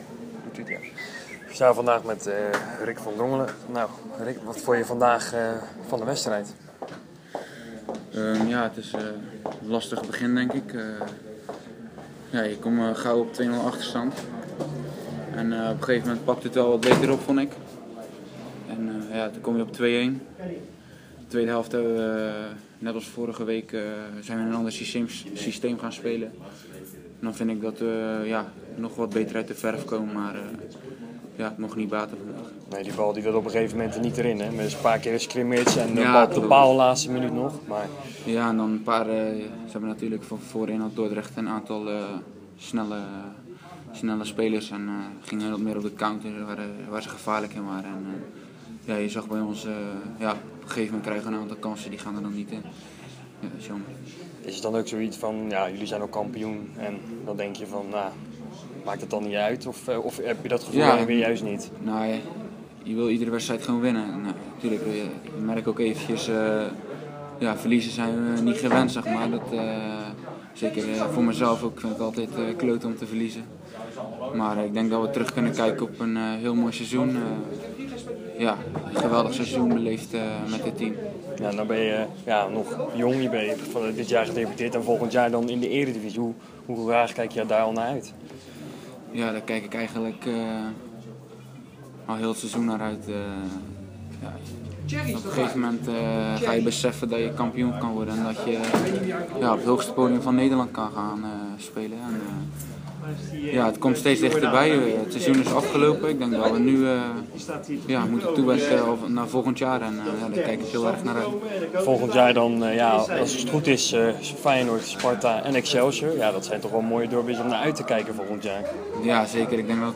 Spartaan Rick van Drongelen was realistisch na de wedstrijd: